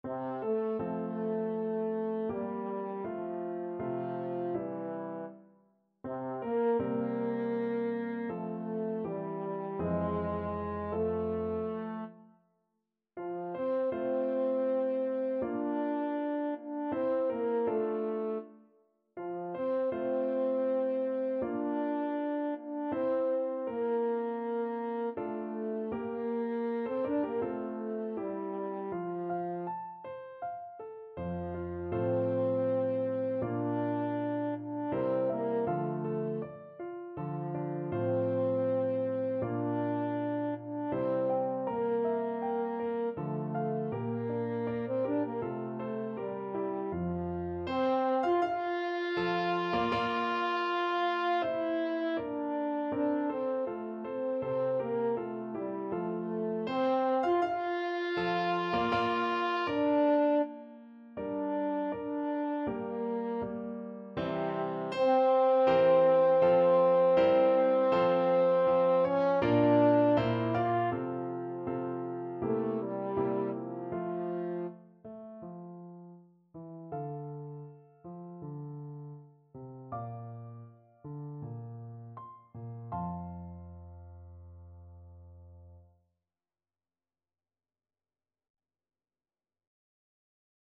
French Horn version
French Horn
F major (Sounding Pitch) C major (French Horn in F) (View more F major Music for French Horn )
C4-F5
4/4 (View more 4/4 Music)
Andante
Classical (View more Classical French Horn Music)